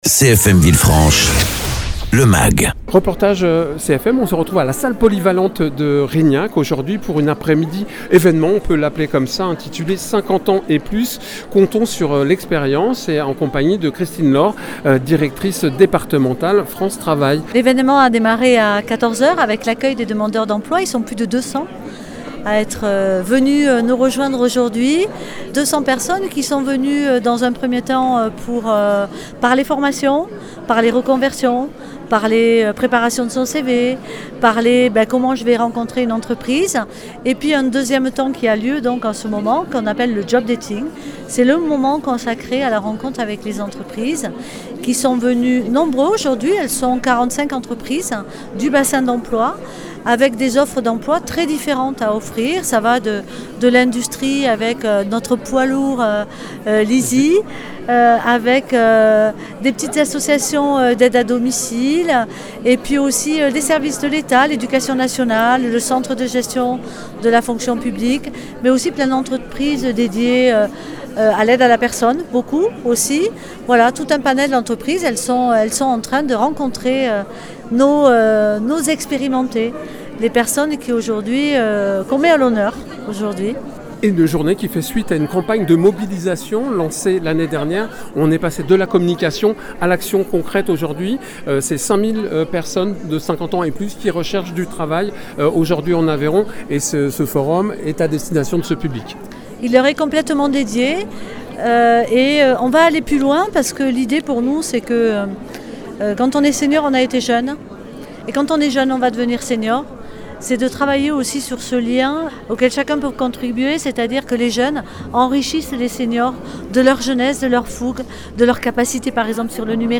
Reportage sur place avec des participants, des recruteurs et des représentants de structures partenaires à propos de cet évènement dédié aux seniors et aux entreprises qui vise à promouvoir une inclusion durables des travailleurs et des travailleuses.
Interviews